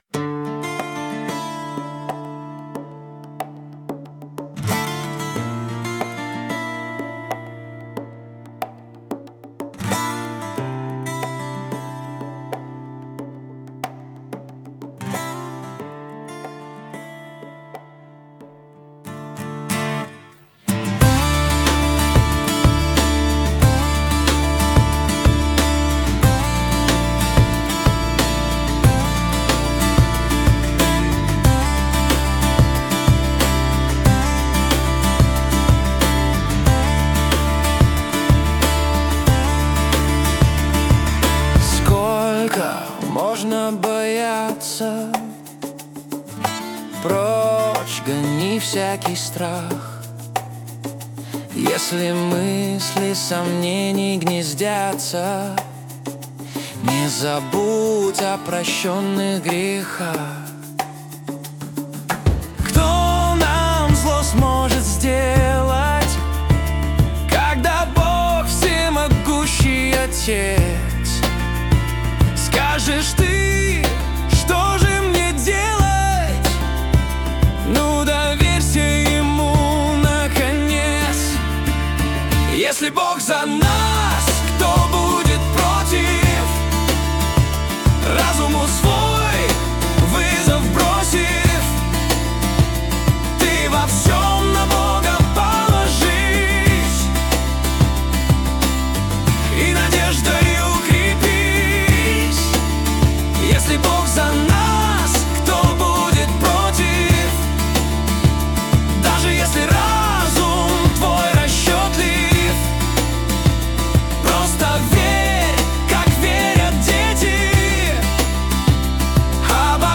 песня ai
183 просмотра 622 прослушивания 53 скачивания BPM: 92